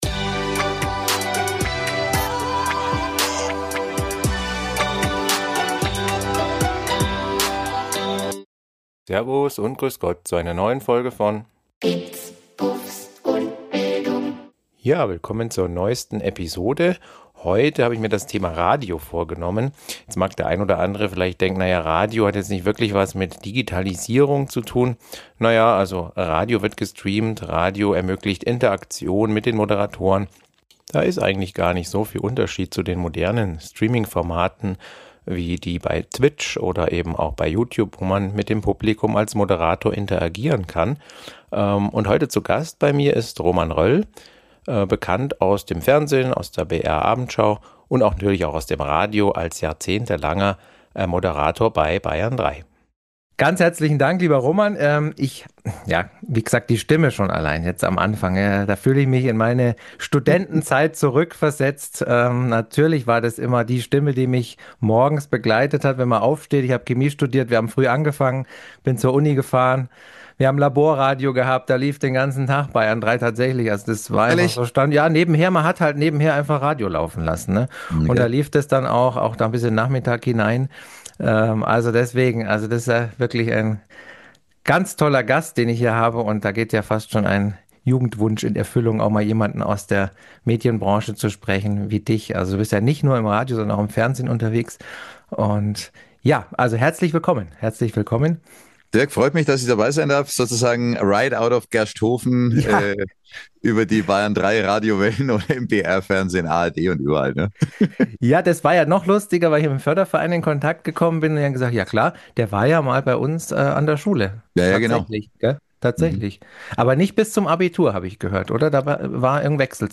#48 Episode 48 IM GESPRÄCH